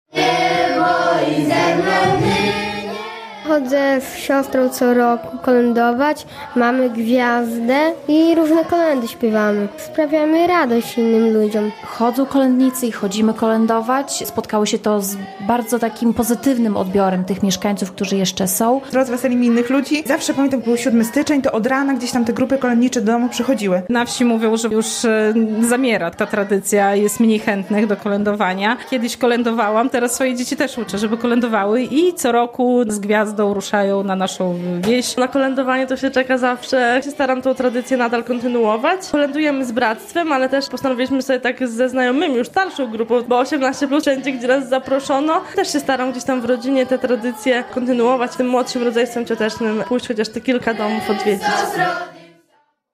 Tradycje kolędnicze prawosławnych - relacja